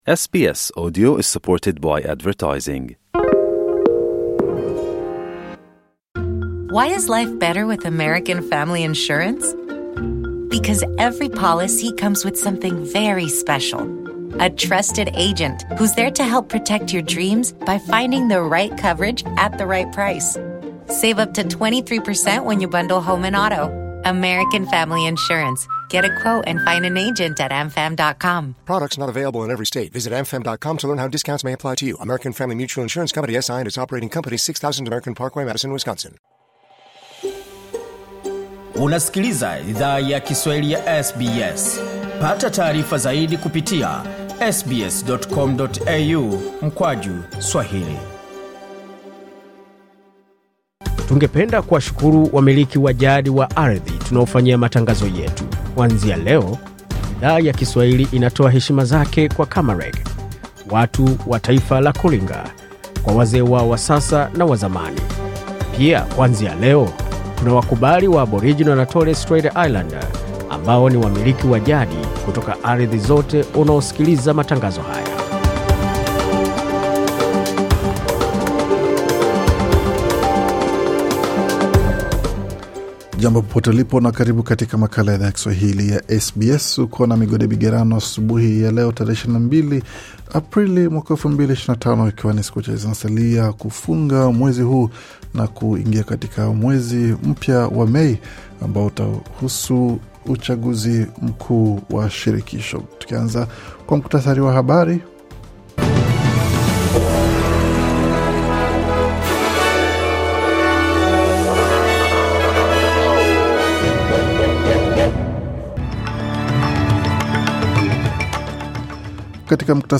Taarifa ya Habari 22 Aprili 2025